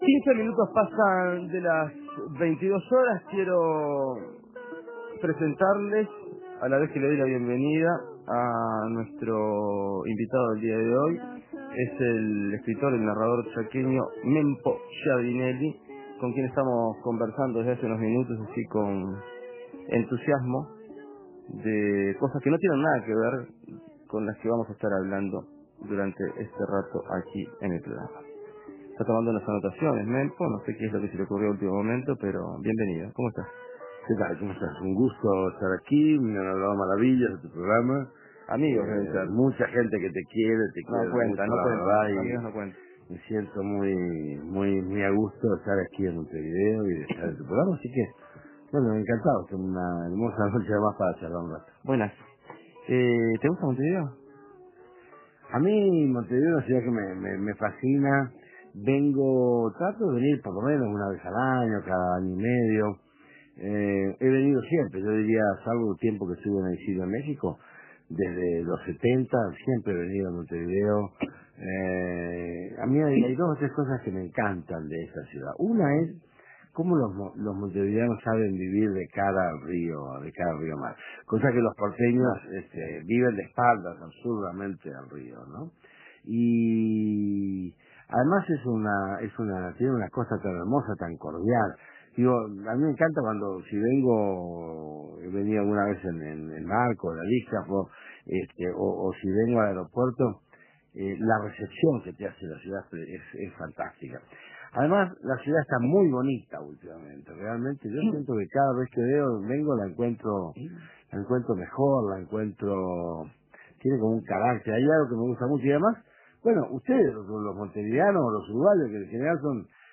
Entrevista con el narrador argentino Mempo Giardinelli, cuyo libro "Luna caliente" fue editado en Uruguay.